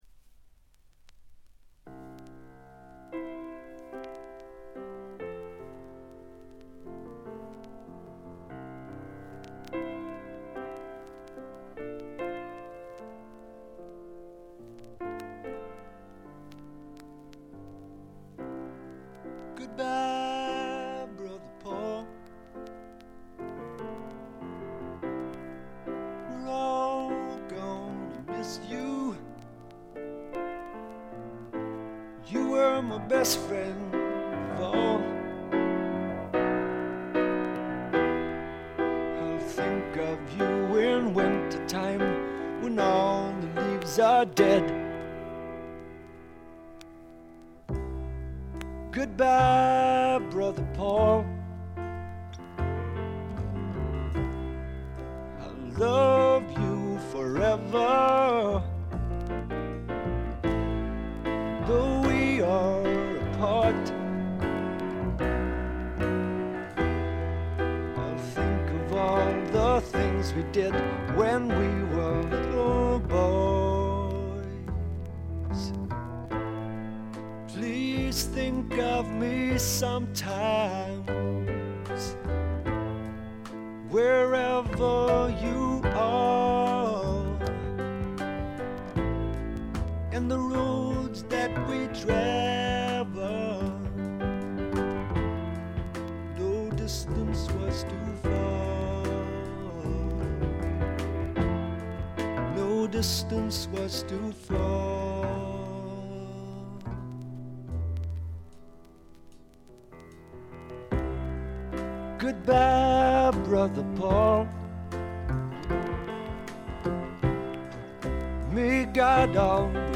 ちょいと鼻にかかった味わい深いヴォーカルがまた最高です。
試聴曲は現品からの取り込み音源です。